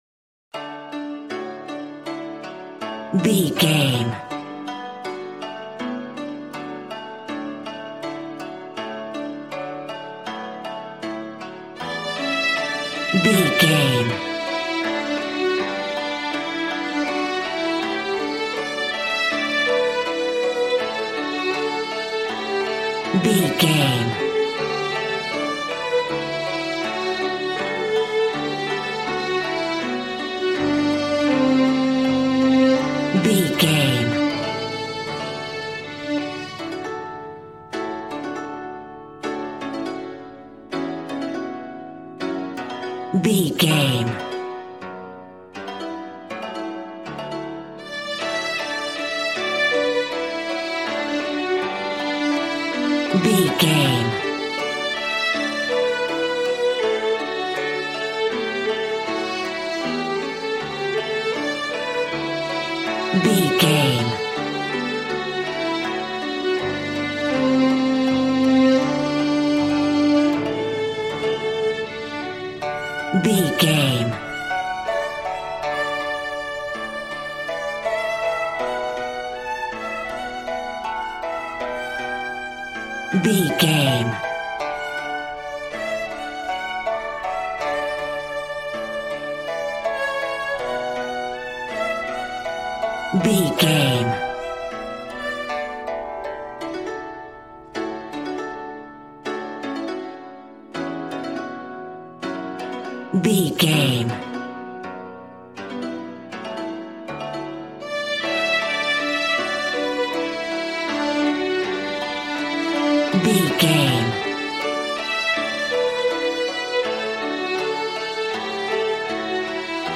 Ionian/Major
D
happy
bouncy
conga